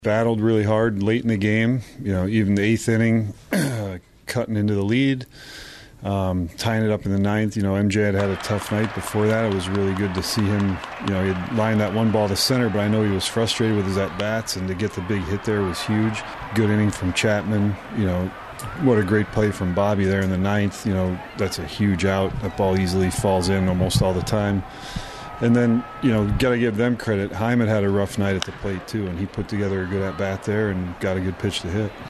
Royals Manager Matt Quatraro said there were some good things last night.